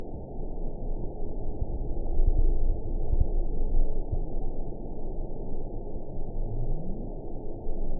event 922322 date 12/29/24 time 22:33:25 GMT (5 months, 2 weeks ago) score 9.12 location TSS-AB09 detected by nrw target species NRW annotations +NRW Spectrogram: Frequency (kHz) vs. Time (s) audio not available .wav